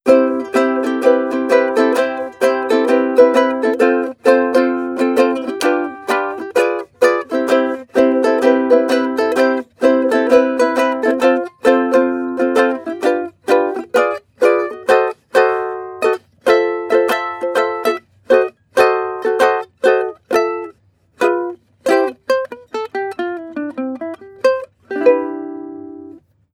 • ukulele strumming sequence.wav
ukulele_strumming_sequence_dxC.wav